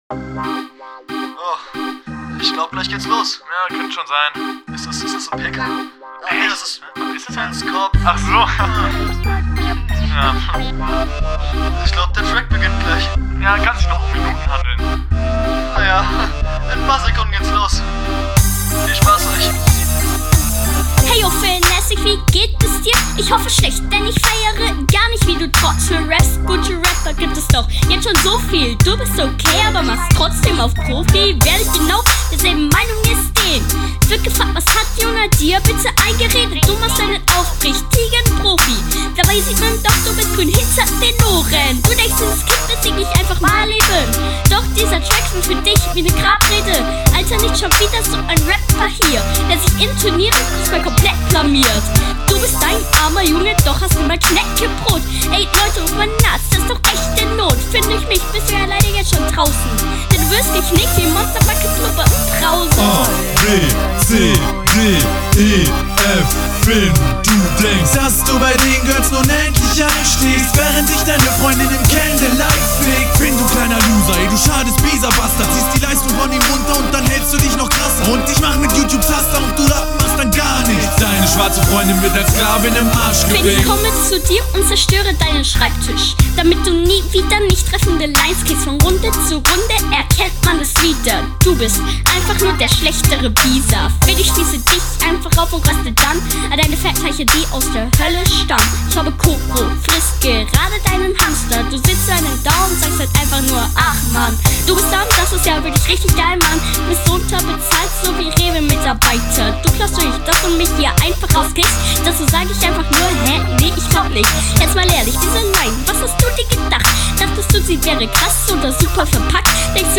Soundqualität ist zwar an sich da, aber an der Verständlichkeit hängts.
Deine Soundqualität ist nicht unbedingt schlecht, jedoch stört die leise Stimme etwas.